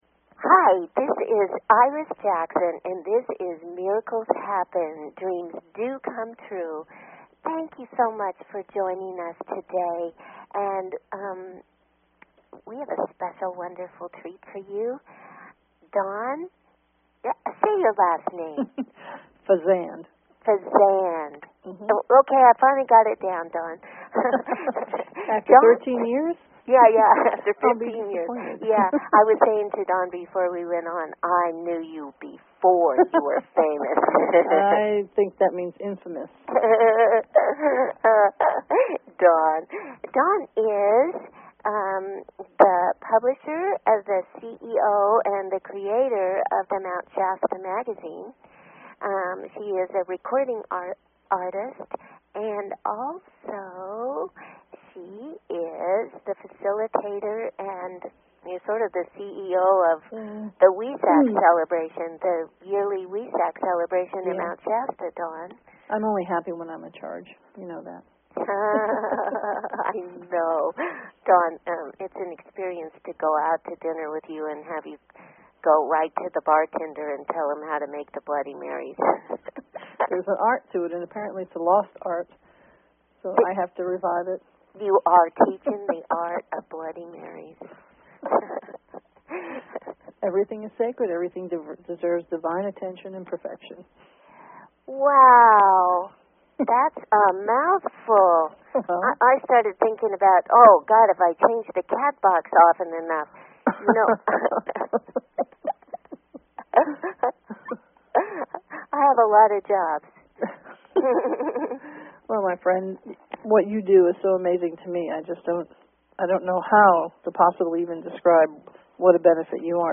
Talk Show Episode, Audio Podcast, Miracles_Happen and Courtesy of BBS Radio on , show guests , about , categorized as